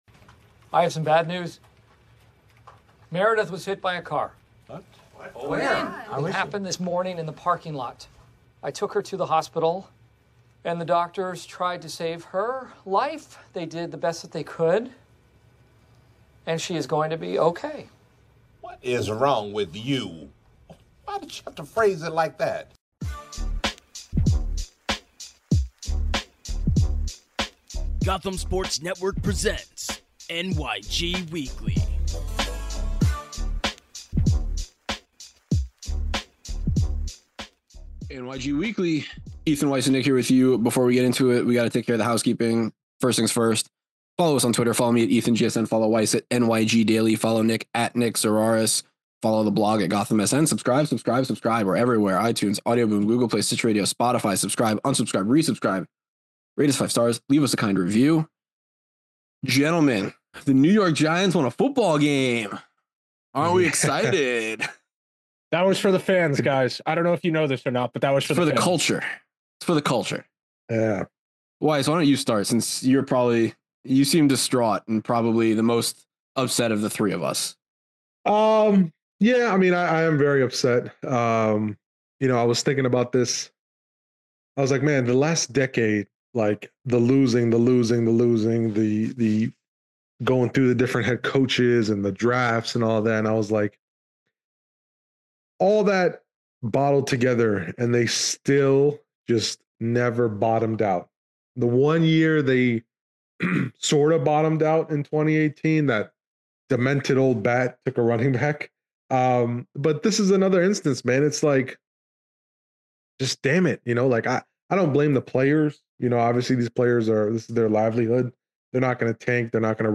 in depth conversation